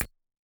click_3.ogg